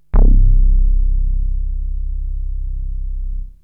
SYNTH BASS-2 0014.wav